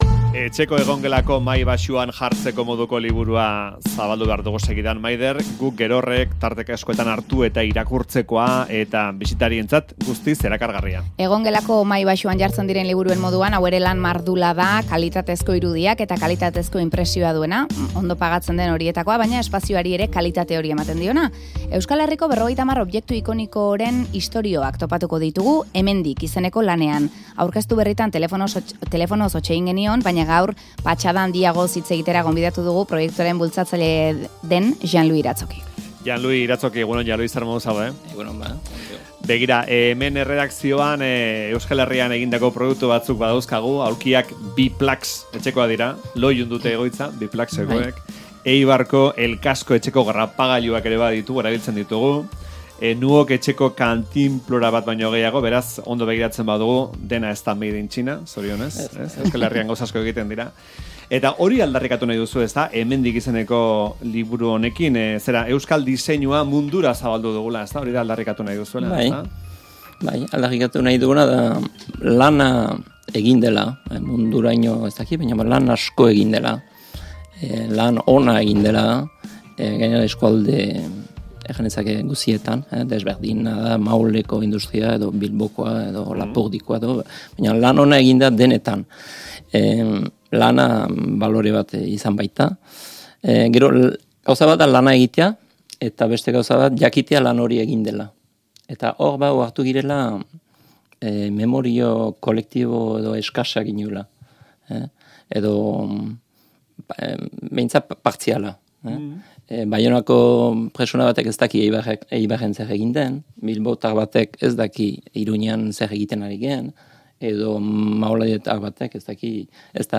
Faktorian izan da elkarrizketaren tartean.